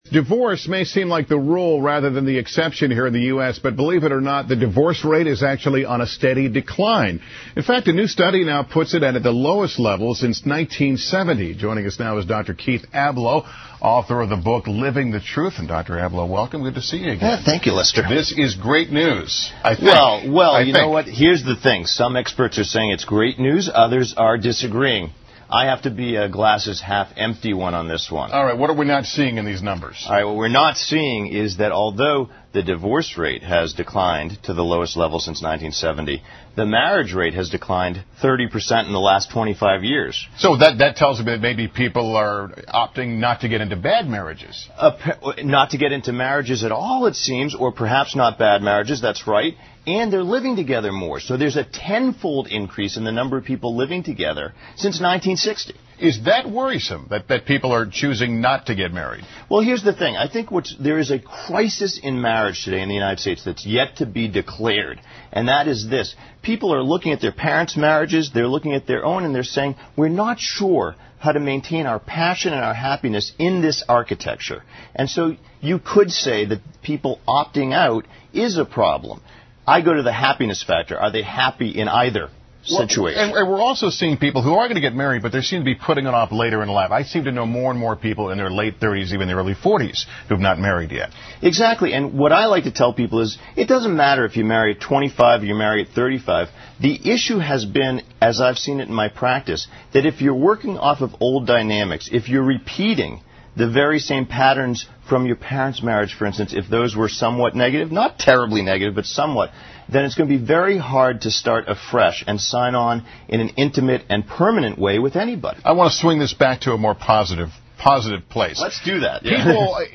访谈录 Interview 2007-05-18&20, 结婚?放聪明点!